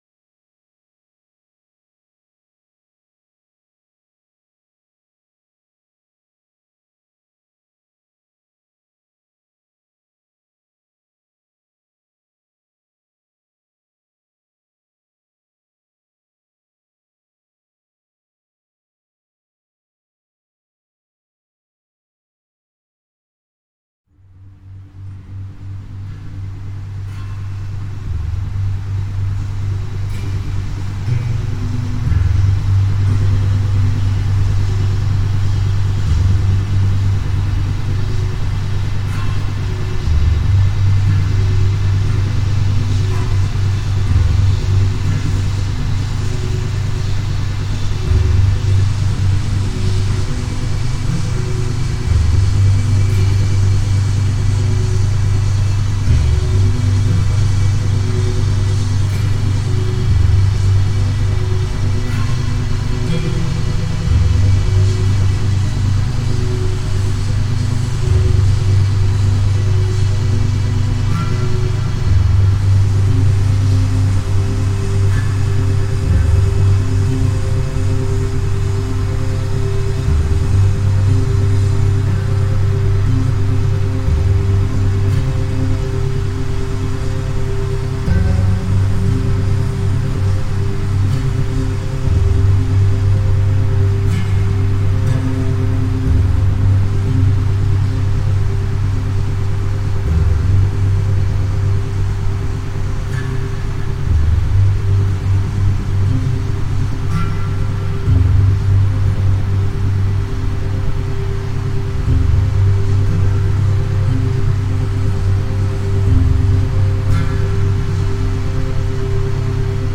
Der QUICK-SLEEPER beinhaltet eine Zusammenstellung von Isochronen Tönen und Binauralen Beats.